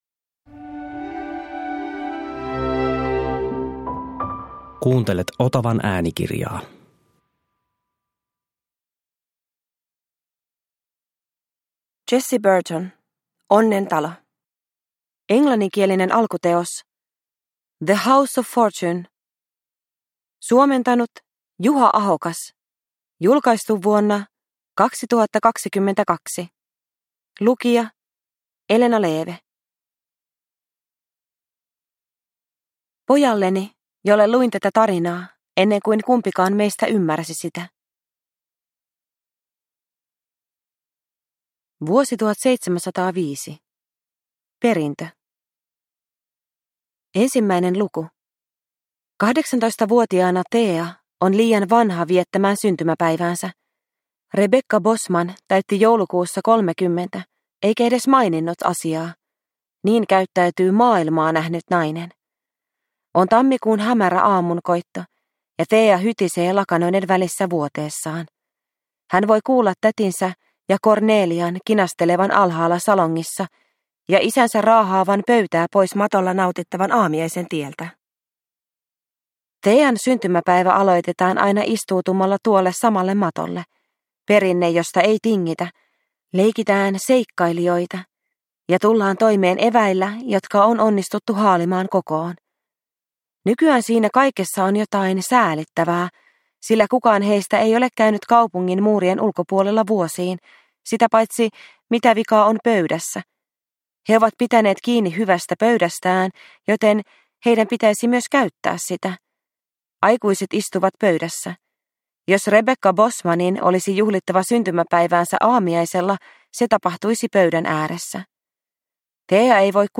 Onnen talo – Ljudbok – Laddas ner
Uppläsare: Elena Leeve